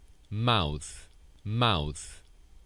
Haz clic para escuchar la pronunciación de las palabras: